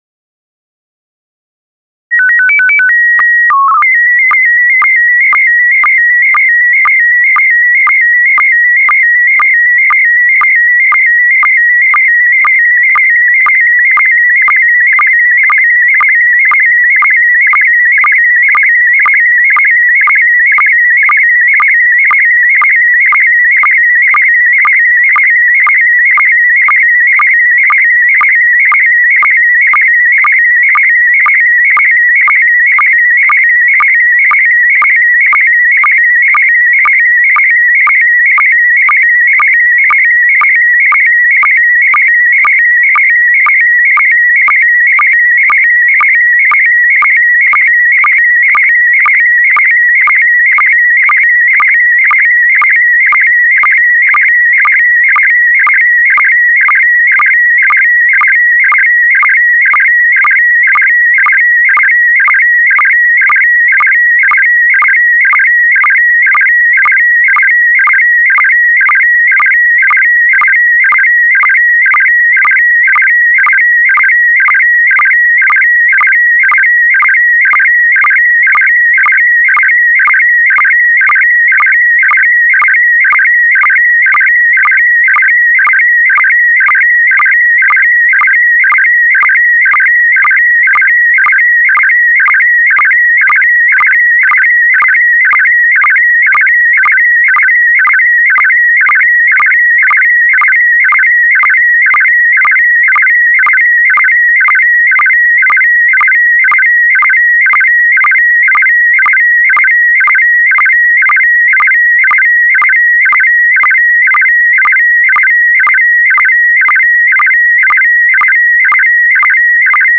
Here is an example of what a usual SSTV transmission sounds like.
sstv_example.wav